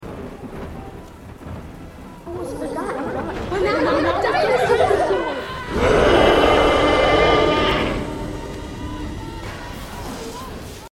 Shelly as rexy roar jumpscare sound effects free download